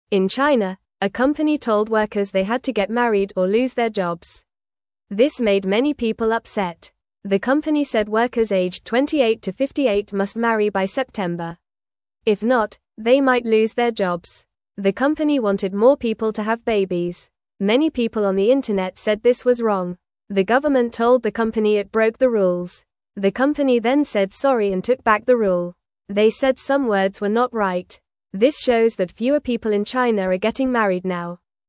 Fast
English-Newsroom-Beginner-FAST-Reading-Company-Makes-Workers-Marry-or-Lose-Their-Jobs.mp3